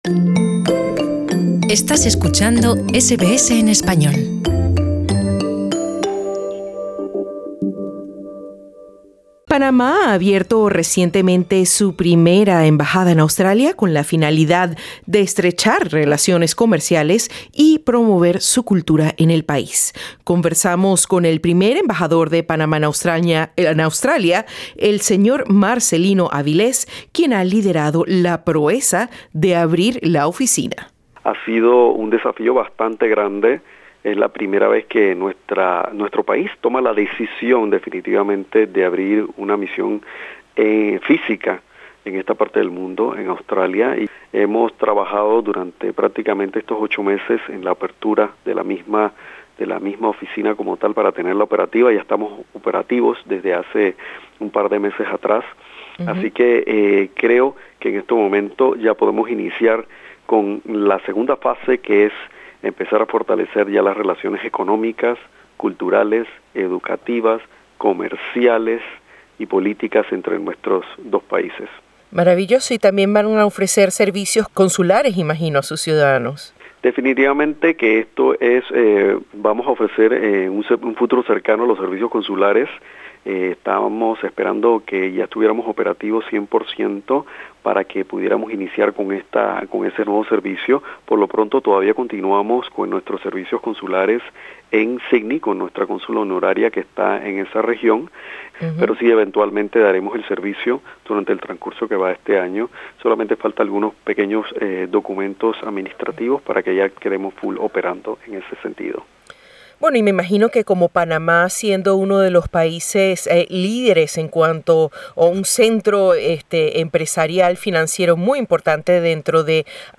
La primera sede diplomática de Panamá en las antípodas acaba de abrir operaciones en Camberra, con el fin de estrechar las relaciones bilaterales del país con Oceanía y ofrecer servicios consulares adicionales a sus ciudadanos. Durante esta semana, la Embajada de Panamá estará organizando una serie de actividades para celebrar el 500 aniversario de la fundación de Ciudad de Panamá. Conversaremos al respecto con el Señor Embajador Marcelino Avilés, quién ha liderado la proeza de abrir la sede.